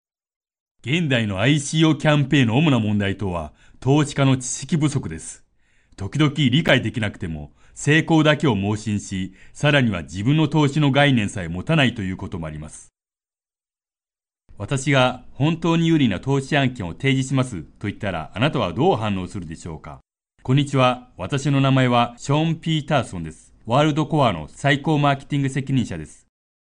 日语样音试听下载
日语配音员（男5）